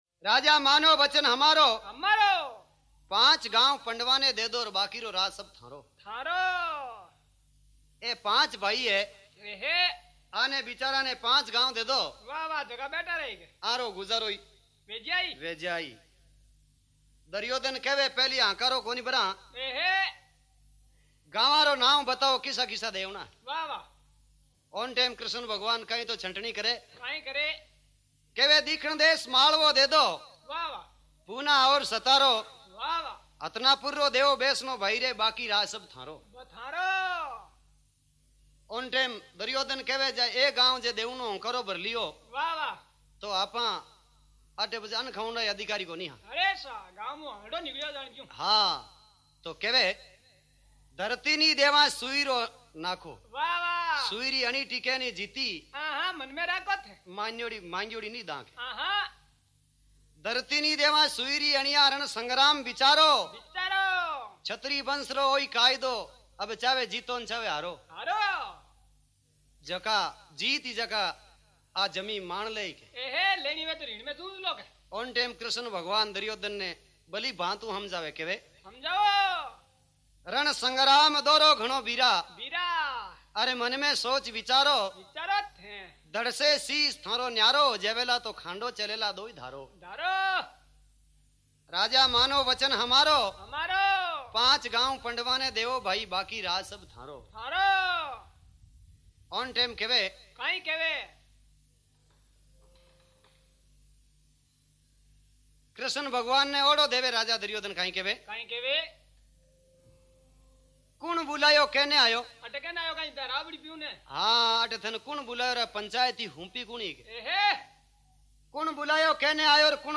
Rajasthani Songs
Prabhati Bhajan